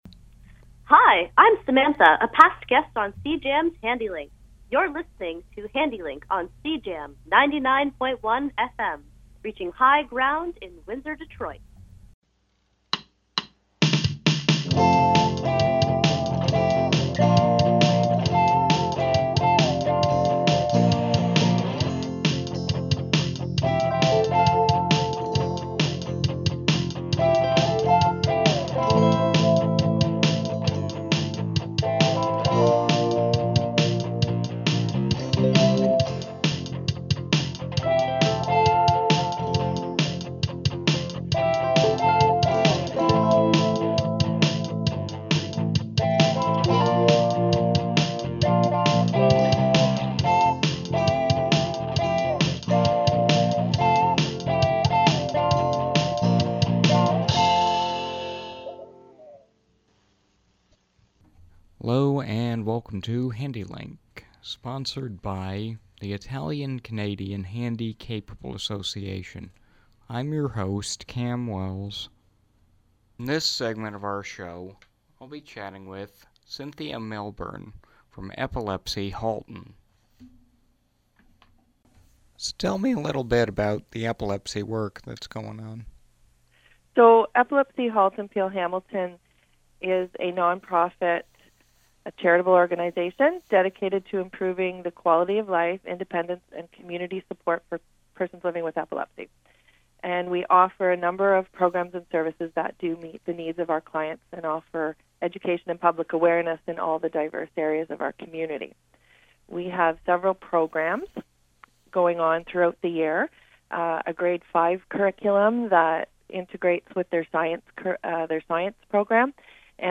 Epilepsy, Yoga panel